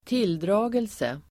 Uttal: [²t'il:dra:gelse]